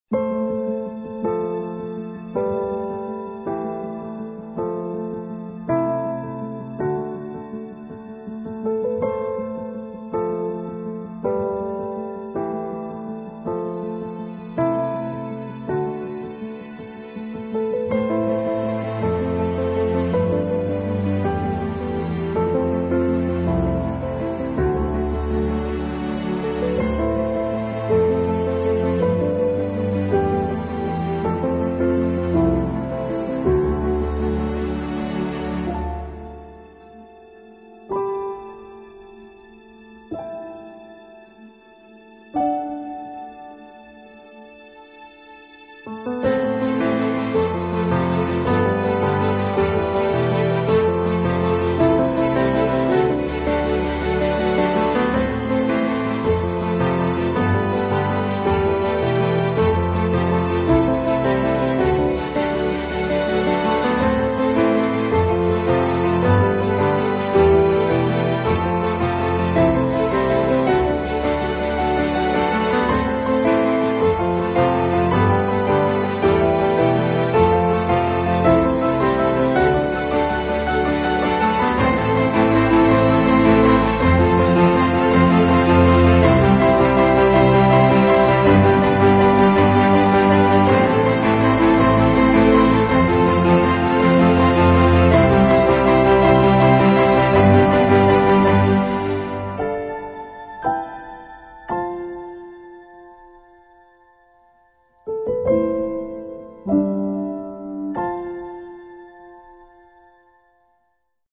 这是一个感人的，但鼓舞人心的主题，包括钢琴和弦乐